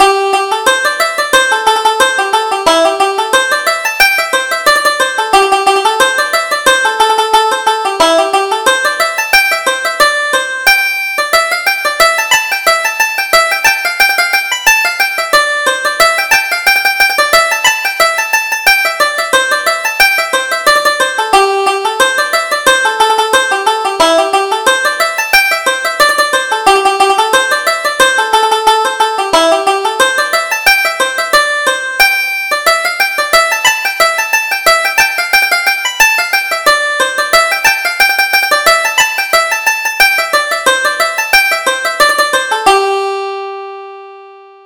Reel: The Old School Master